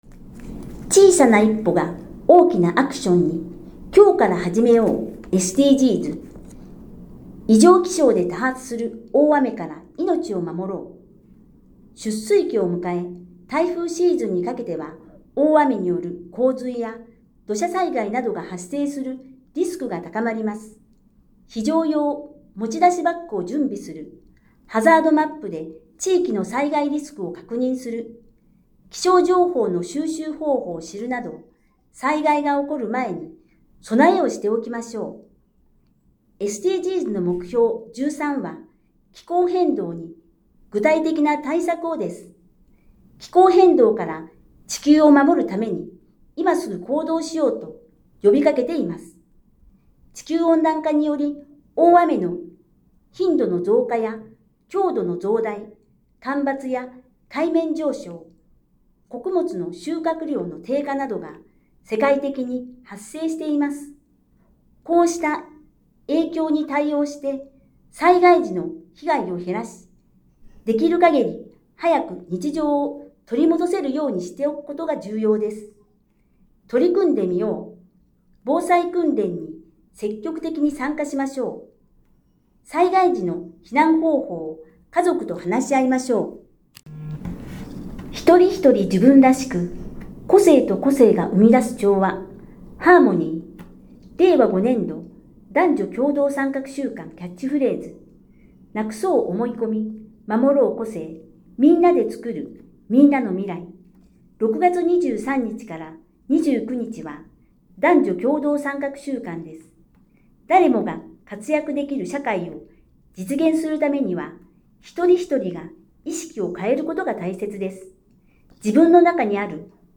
音声欄に表示があるものは、「声の広報」として音声で聞くことができます。